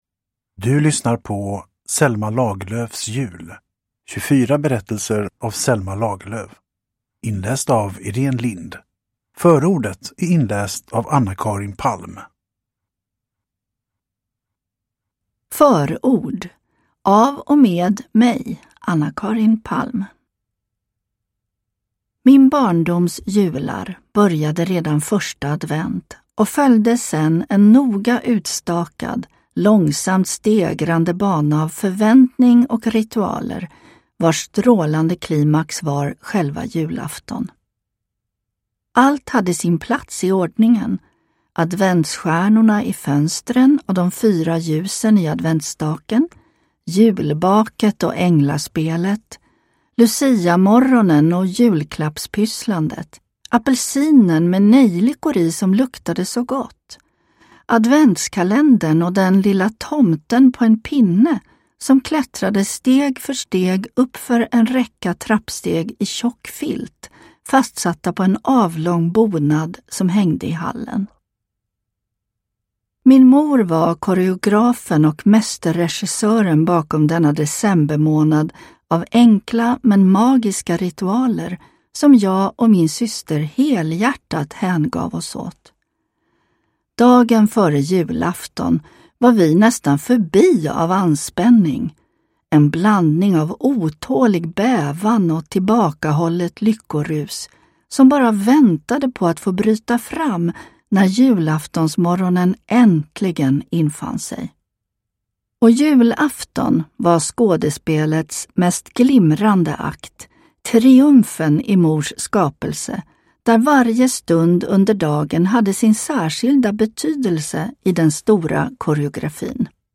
Selma Lagerlöfs jul : 24 julberättelser – Ljudbok